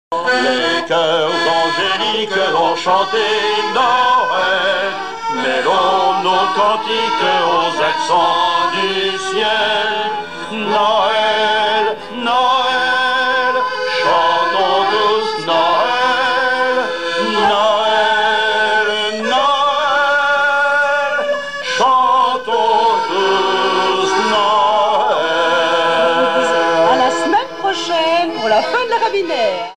Noël, Nativité
Genre strophique
Pièce musicale inédite